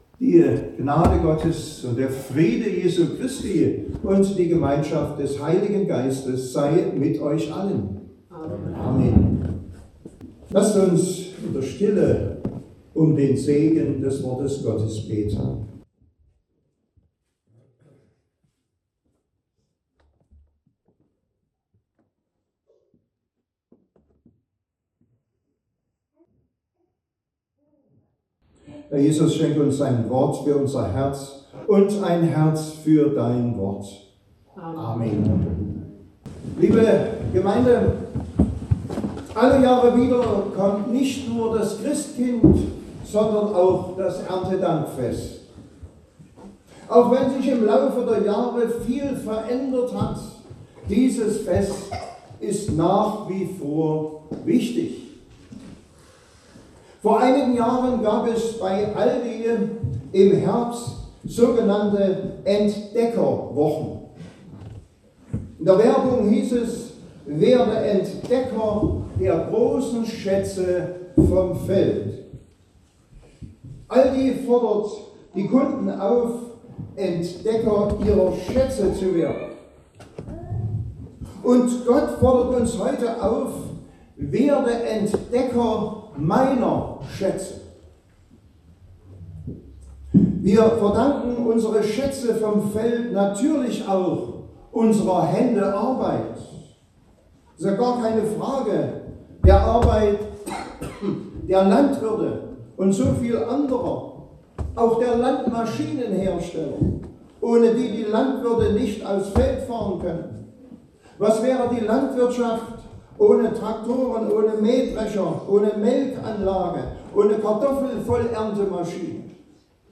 Mose 8, 7-18 Gottesdienstart: Predigtgottesdienst 40 Jahre zog das Volk Israel durch die Wüste und lebte als Dauercamper.